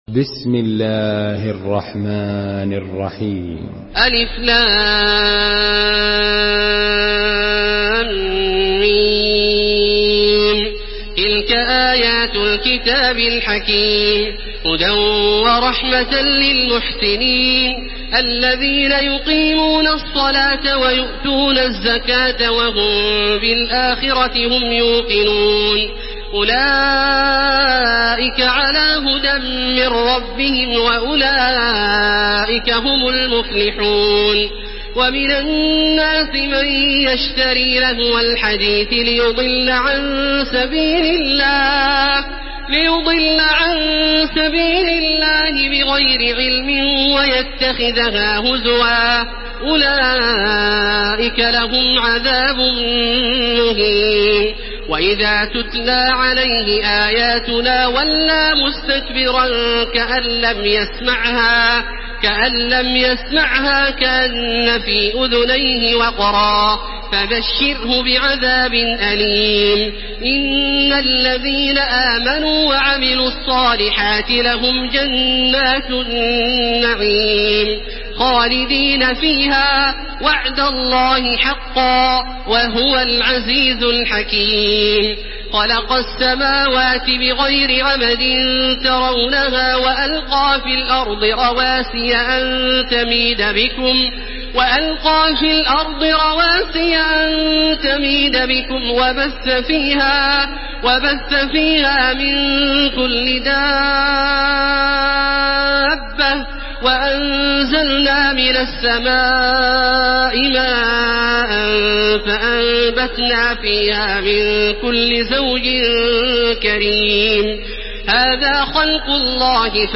Surah Lokman MP3 in the Voice of Makkah Taraweeh 1431 in Hafs Narration
Murattal